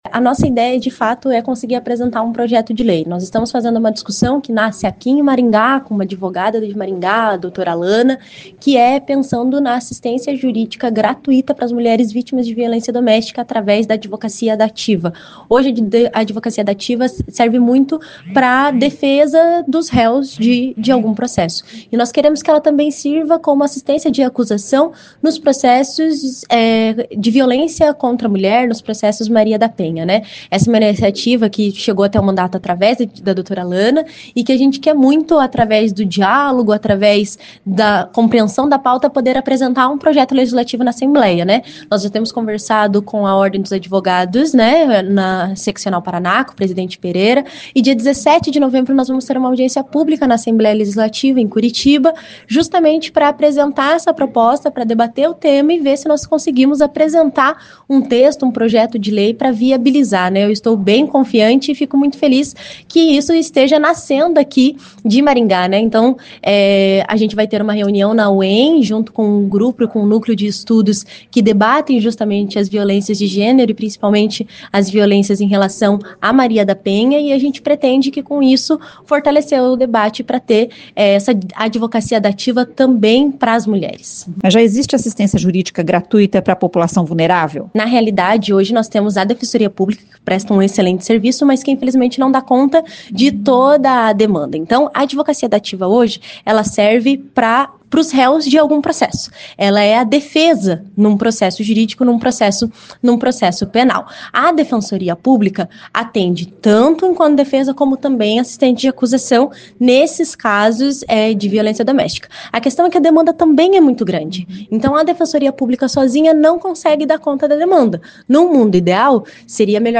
Ouça o que diz a deputada Ana Júlia: